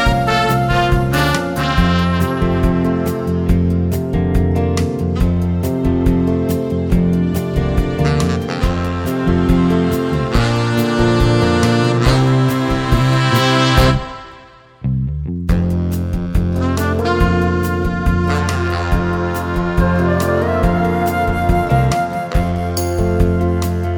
No Harmony Pop (1960s) 4:27 Buy £1.50